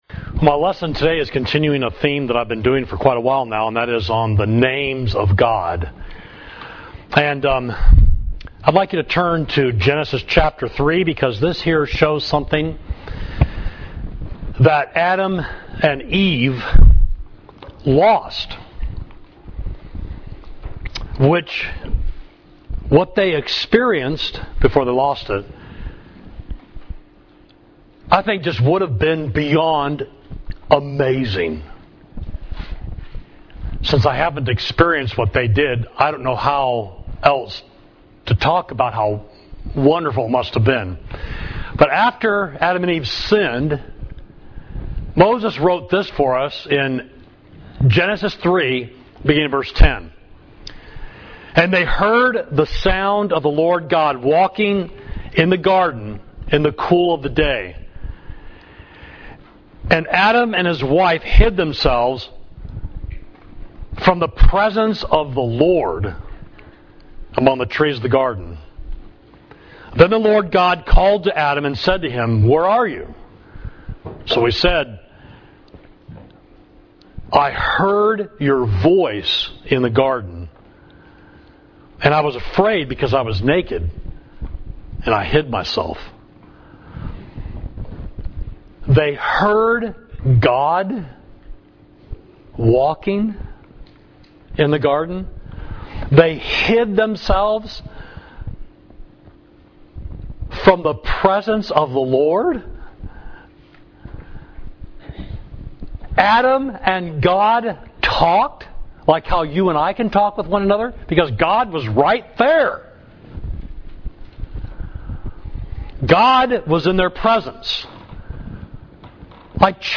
Sermon: Names of God – Immanuel: God with Us